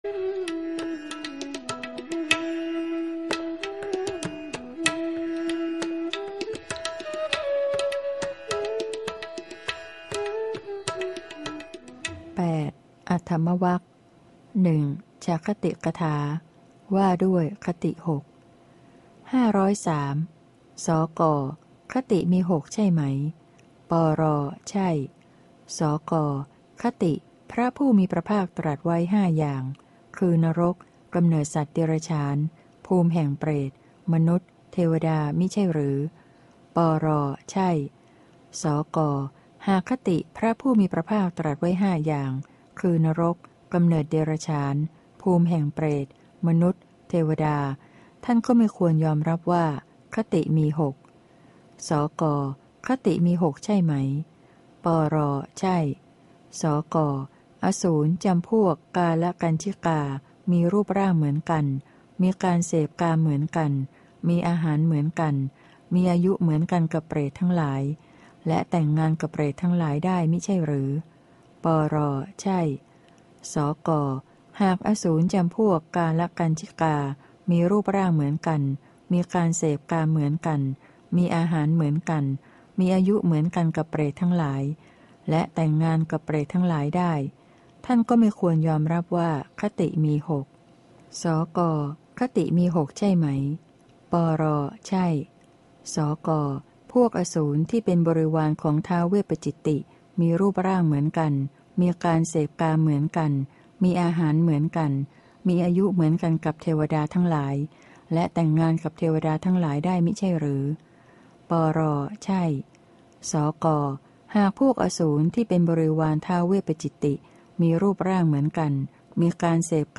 พระไตรปิฎก ภาคเสียงอ่าน ฉบับมหาจุฬาลงกรณราชวิทยาลัย - เล่มที่ ๓๗ พระอภิธรรมปิฏก